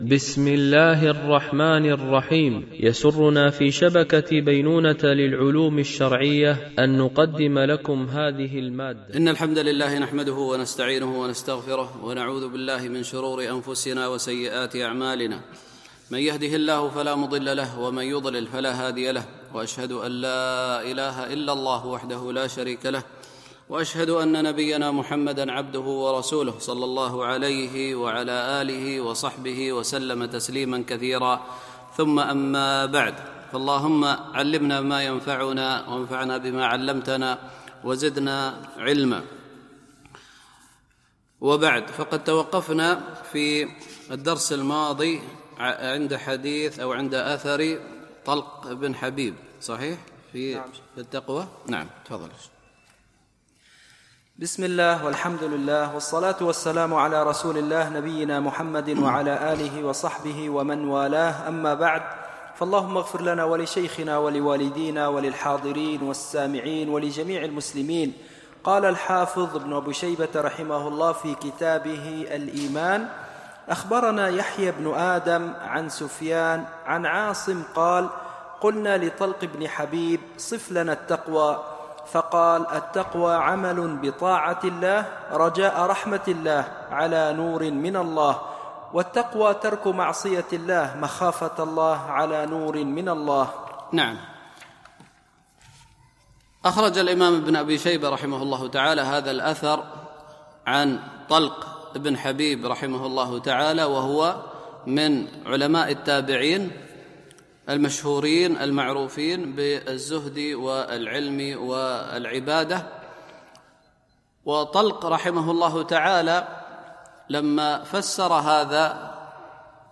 شرح كتاب الإيمان لابن أبي شيبة ـ الدرس 25
التنسيق: MP3 Mono 44kHz 64Kbps (VBR)